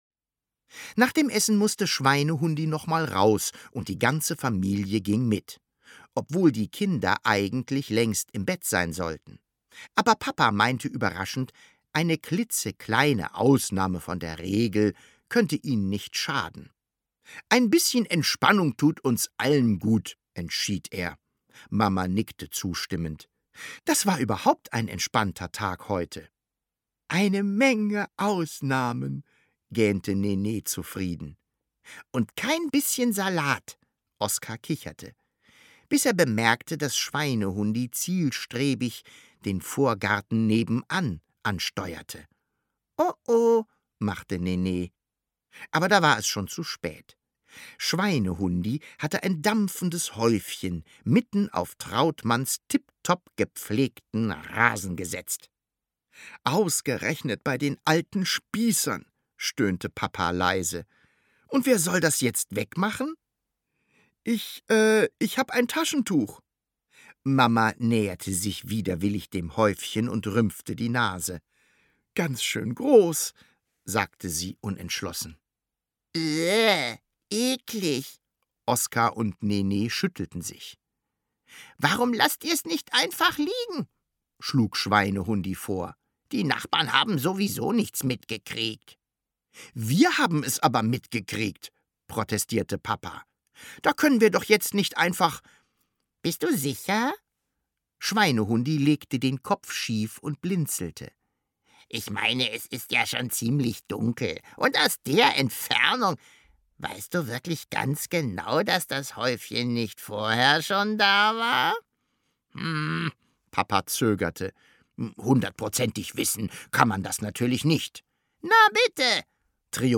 Von Schweinehundi lernen heißt Entspannen lernen – Witziges Hörbuch für die ganze Familie Das Grauen für alle Erzieher:innen!